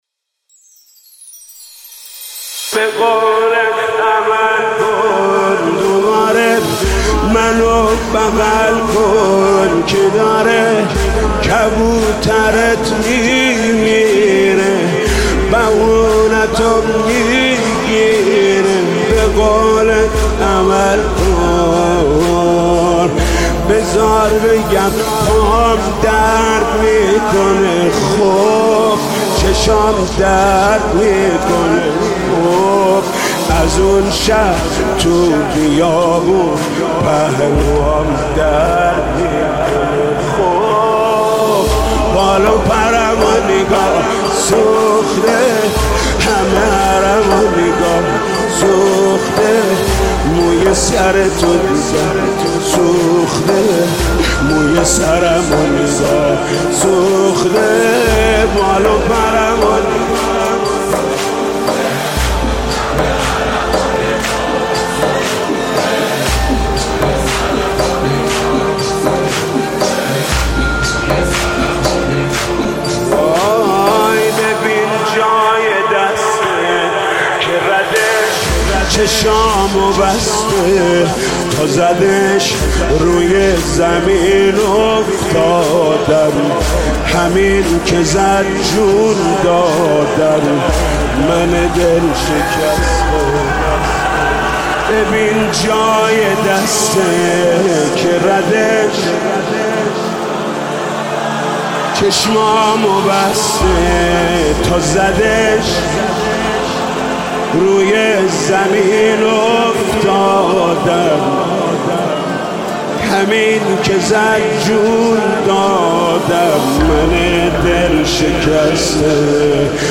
مداحی شب سوم محرم